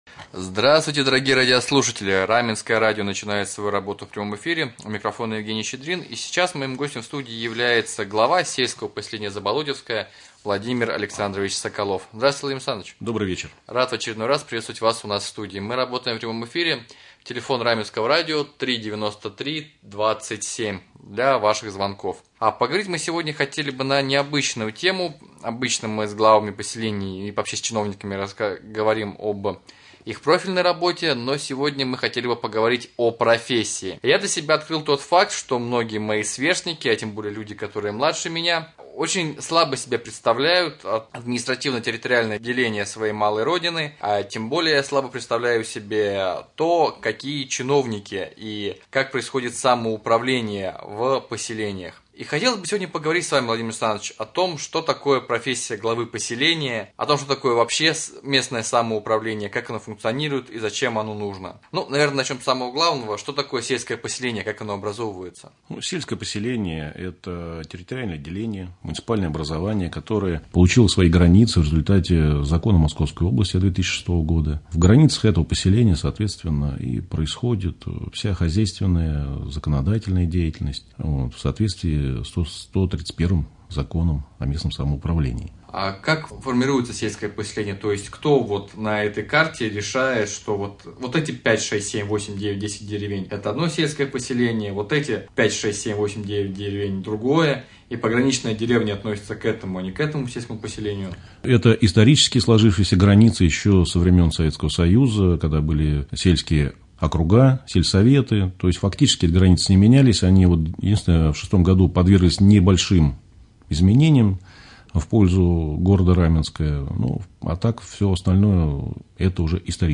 Прямой эфир. Гость студии глава сельского поселения Заболотьевское В.А.Соколов.